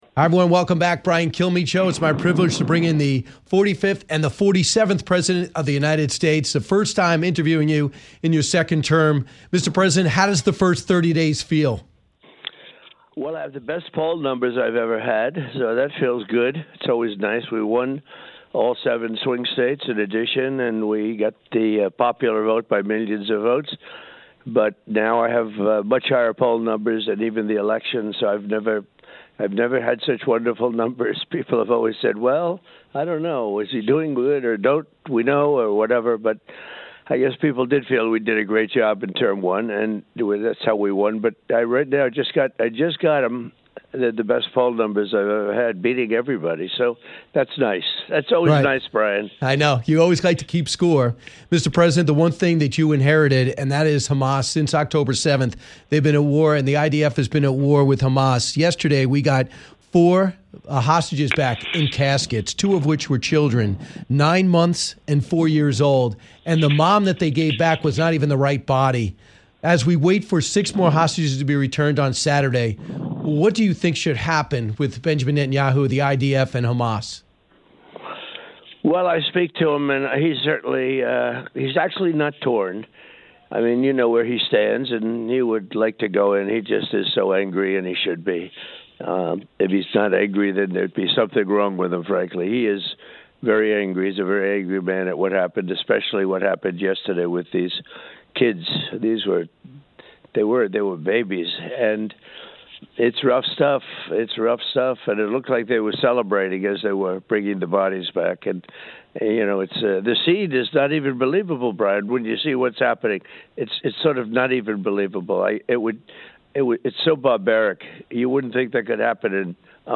President Donald Trump joins Brian for a wide ranging interview in which they discuss his war of words of President Zelenskyy and how he thinks Russia should proceed from here with peace talks. The President also weighed in on the status of his "one big beautiful bill" and the outcome of the USA vs .Canada 4 Nations Face Off.